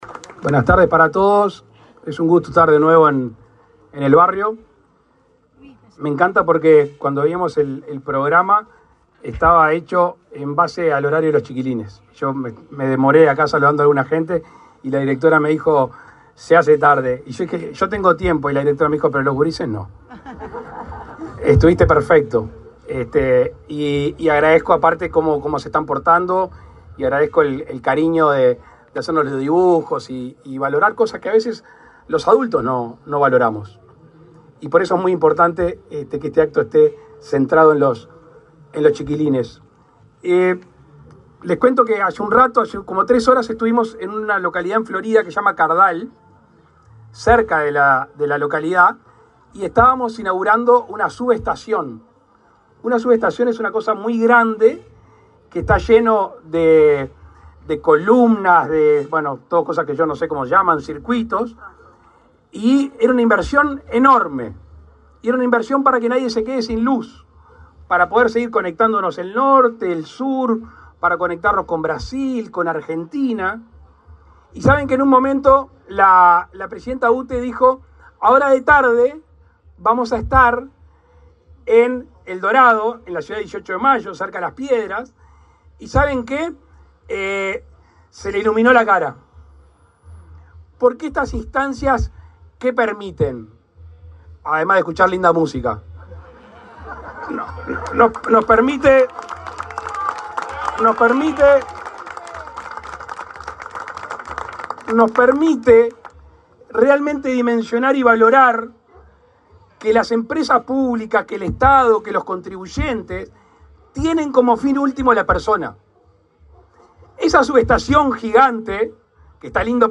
Palabras del presidente Luis Lacalle Pou
El presidente Luis Lacalle Pou encabezó, este viernes 12, la inauguración de obras de electrificación realizadas por UTE, en el marco del Plan de